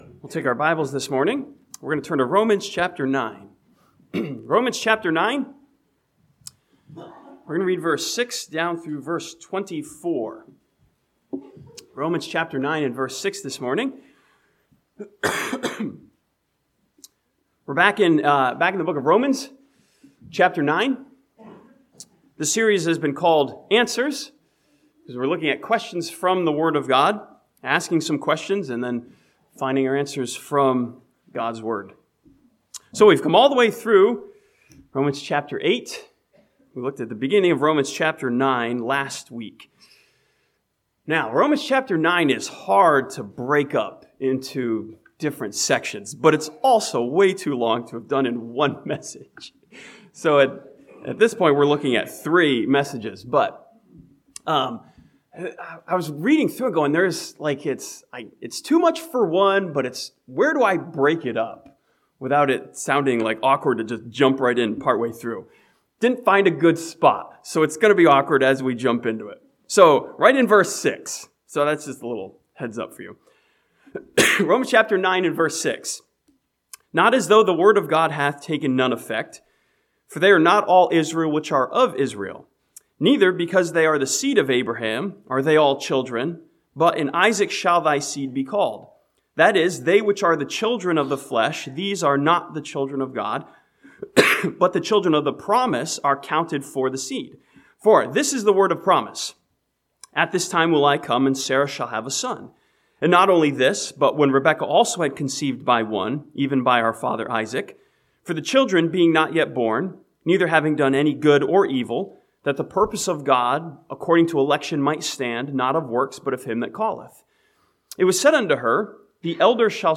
This sermon from Romans chapter 9 challenges believers to ask the question, "how did I get here?"